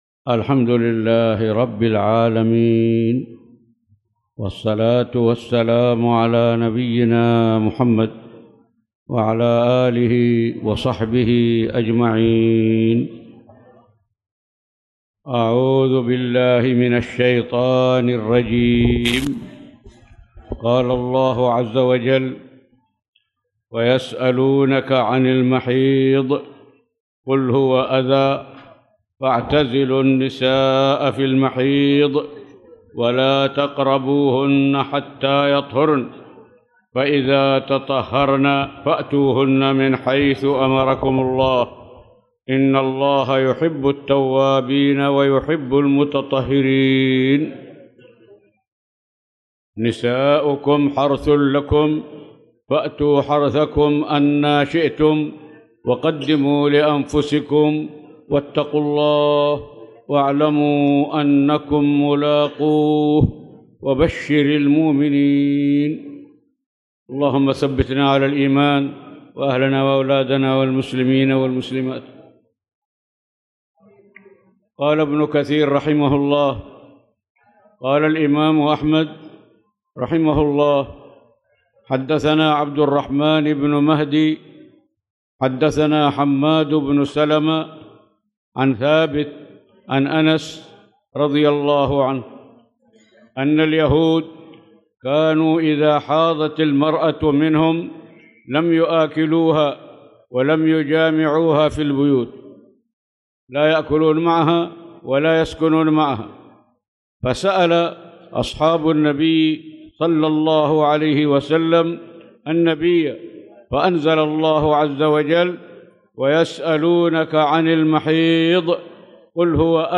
تاريخ النشر ٢٢ ربيع الثاني ١٤٣٨ هـ المكان: المسجد الحرام الشيخ